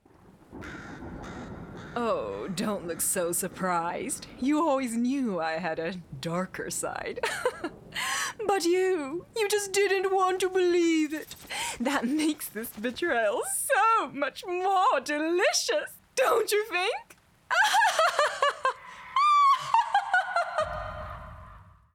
– 声優 –
魔女